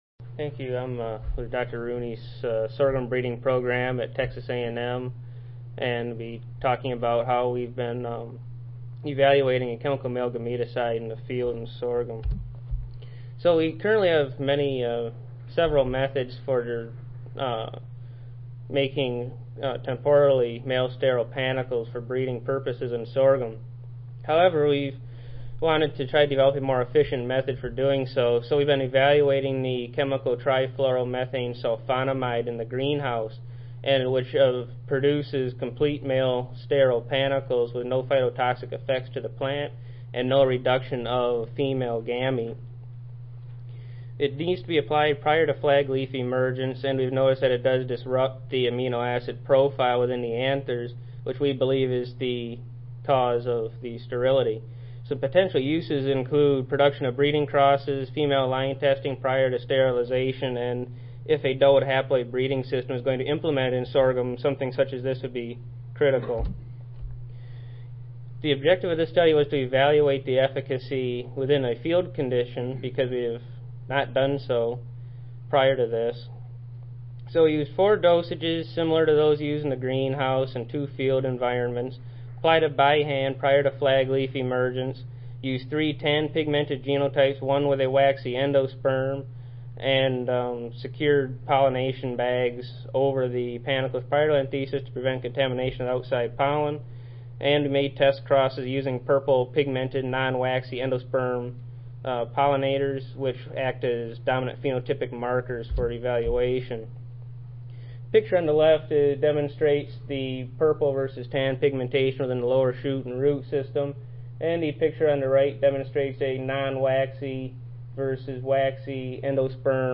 Oral Session
Audio File Recorded Presentation